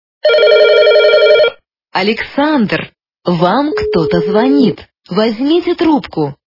» Звуки » Люди фразы » Александр! - Вам кто-то звонит
При прослушивании Александр! - Вам кто-то звонит качество понижено и присутствуют гудки.